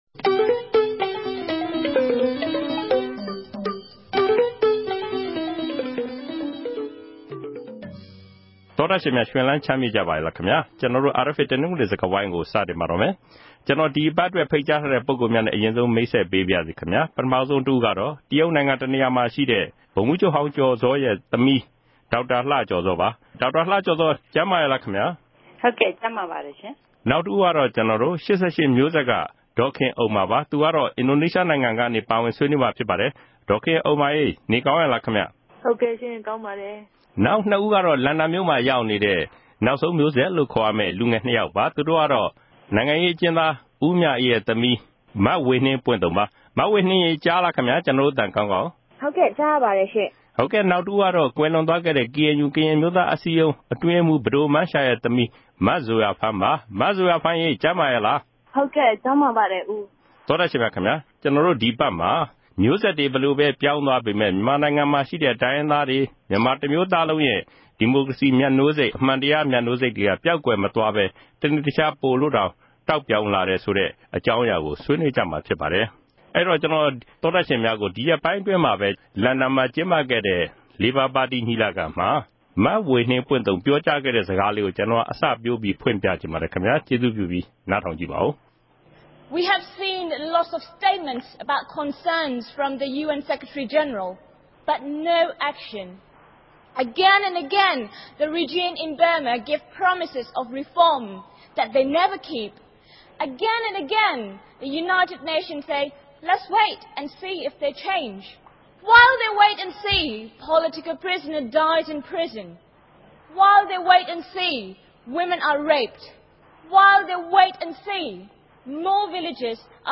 တနင်္ဂနွေဆွေးနွေးပွဲ စကားဝိုင်း